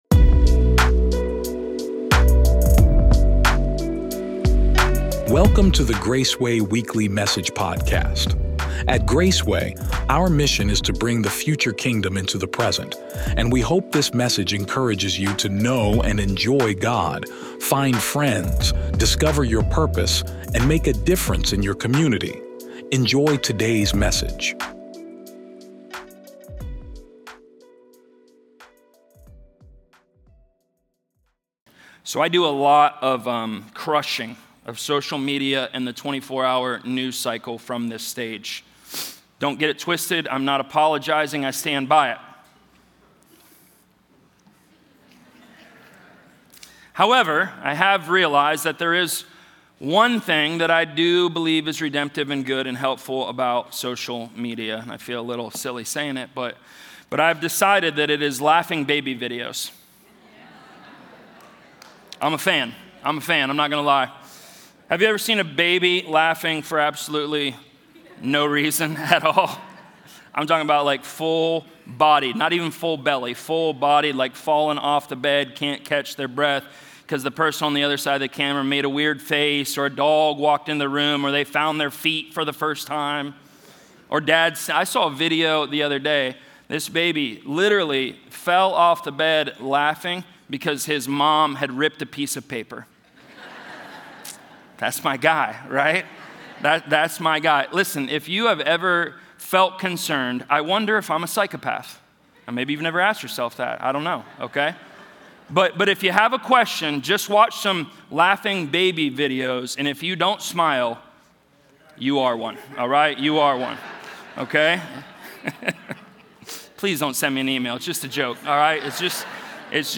Drawing from Scripture across both the Old and New Testaments, this sermon shows how joy flows from abiding in Christ, trusting God through suffering, and living under the reign of King Jesus.
Weekend Messages